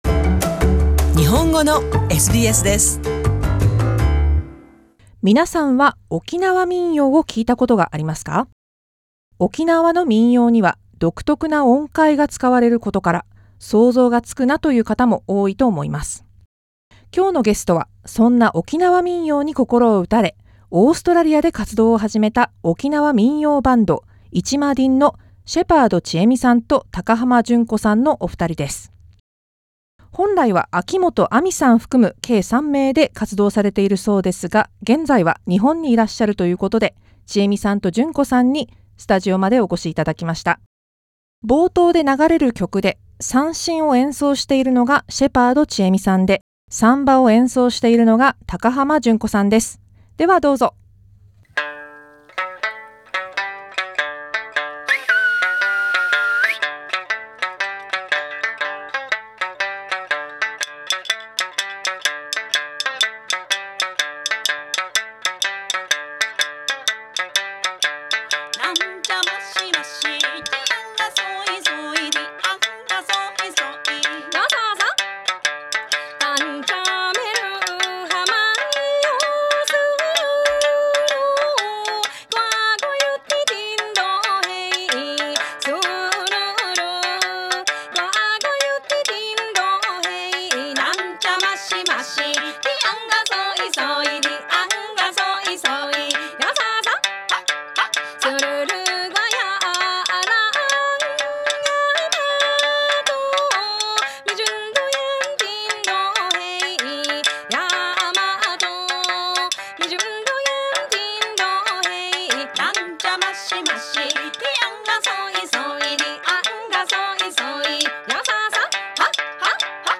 SBS interviewed what Okinawa music is, what are Sanshin and Sanba, why they started to create a band in Melbourne.